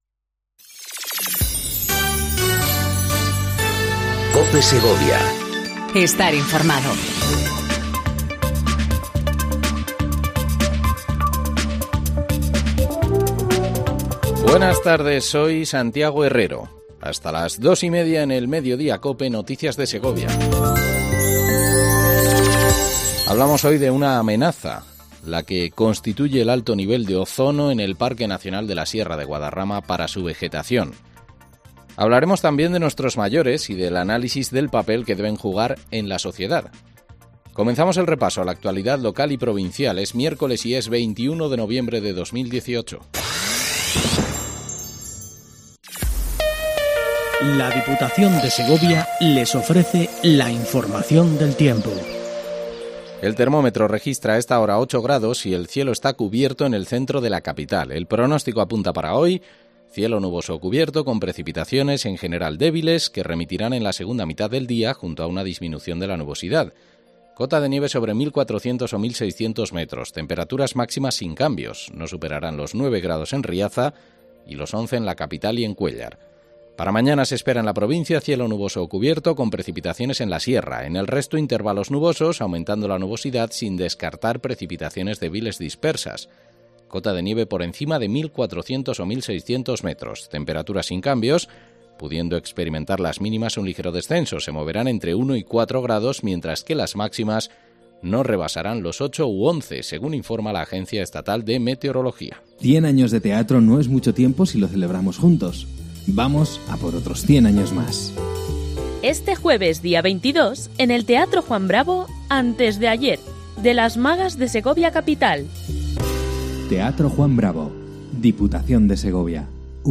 INFORMATIVO MEDIODÍA COPE SEGOVIA 14:20 DEL 21/11/18